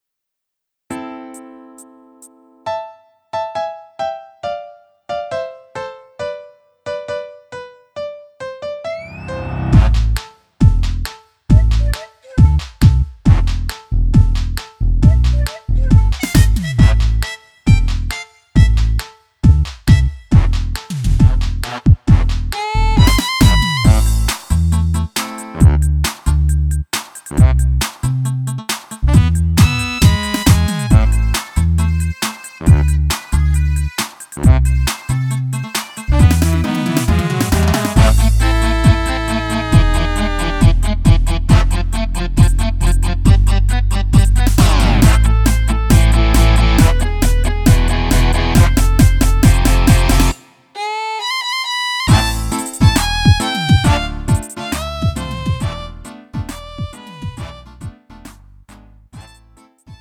가요
Lite MR